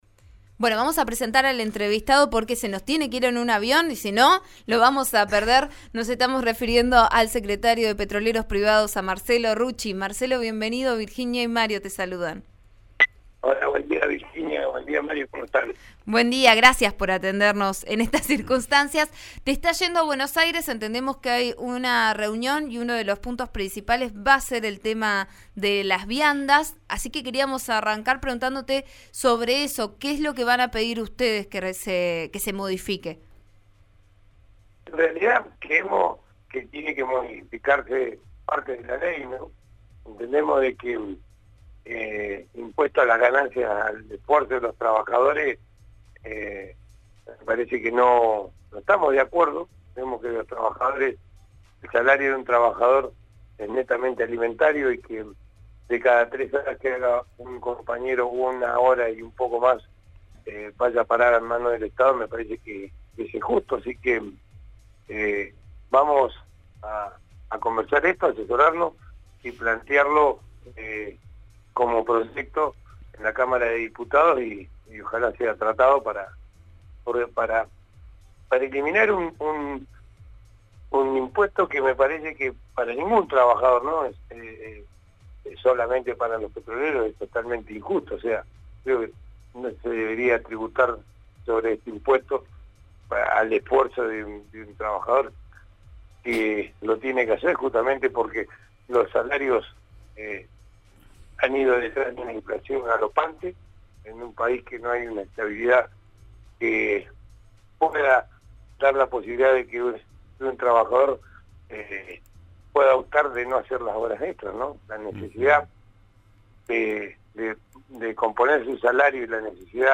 El sindicalista, en declaraciones a Vos A Diario por RN Radio, aseguró que está «muy tranquilo» y que cuenta con documentación necesaria para presentar ante la justicia.